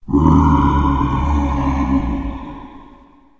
spawners_mobs_mummy.2.ogg